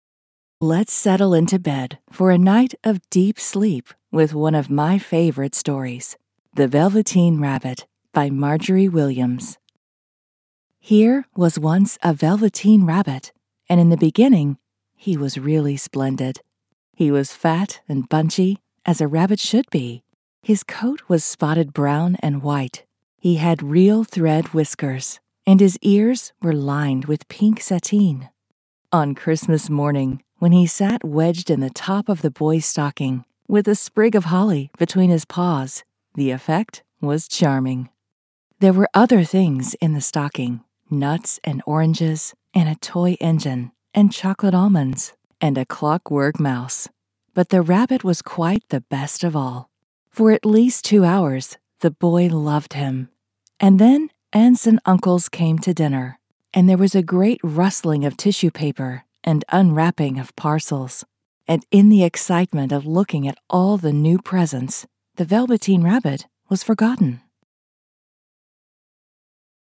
Our audiobook publishing services provide professional narration, sound engineering, and production to create a high-quality listening experience that reflects your voice and message.